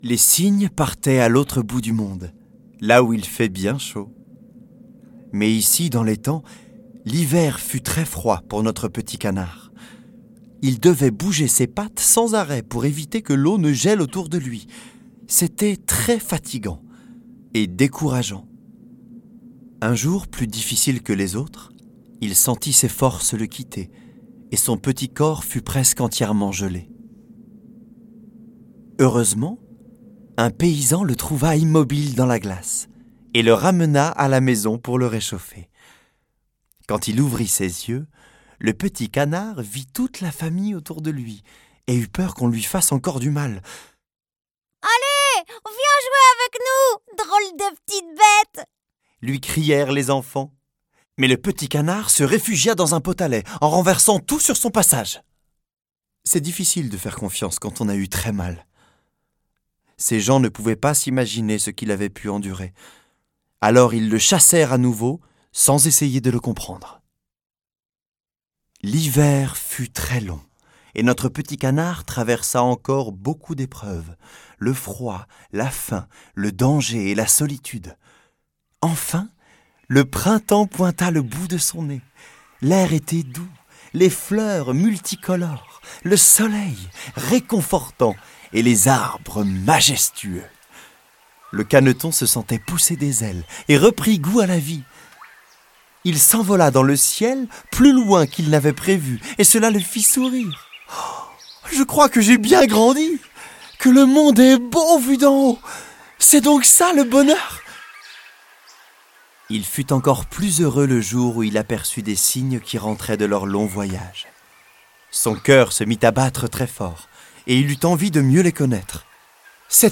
2023-03-10 Ce livre audio invite votre enfant à redécouvrir les contes de Boucle d'Or et les trois ours. Mais aussi, Le Vilain Petit Canard, Le Petit Chaperon rouge et Le Petit Poucet.
Interprétés par 4 comédiens-chanteurs, les contes sont ponctués de ref...